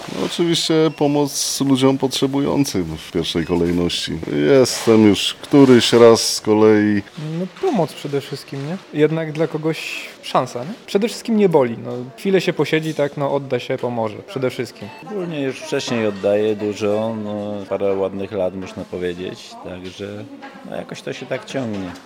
Dawców zapytaliśmy, dlaczego zdecydowali się przyjść do Regionalnego Centrum Krwiodawstwa i Krwiolecznictwa w tym czasie.